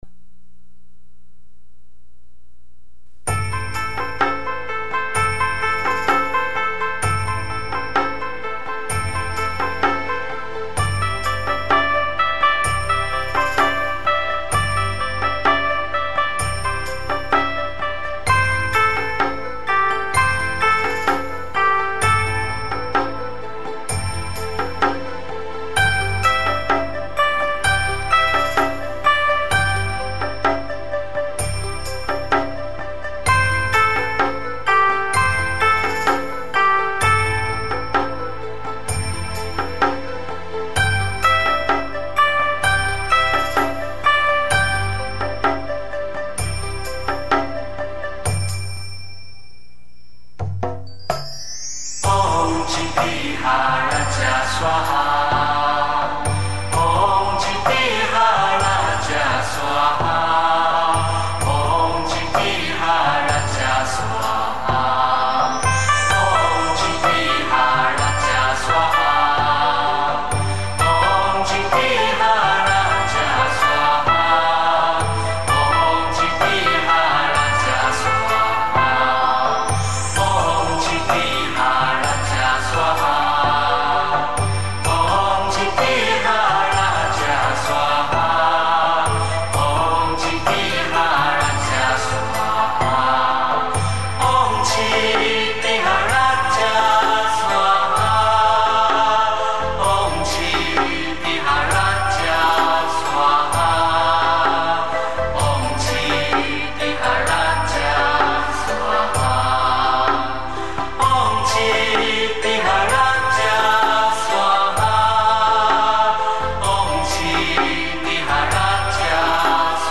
[15/4/2010]嘹亮悦耳的唱诵：地藏王菩萨心咒（并附：著名歌手陈星的演唱【南无大愿地藏王】）